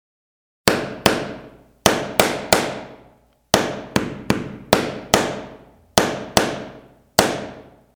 zvuk-molotka_006
zvuk-molotka_006.mp3